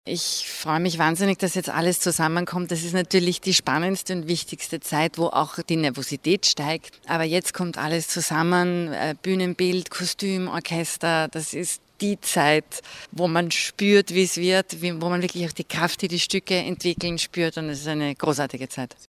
O-Töne - Pressetag - News